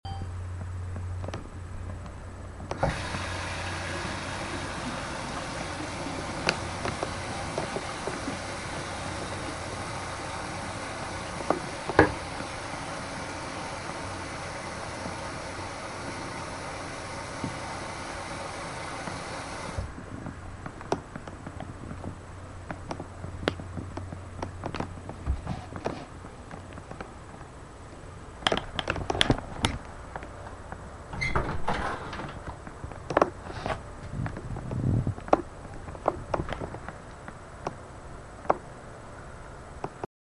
Sounds heard: water running, towel (sort of indistinctly), door creaking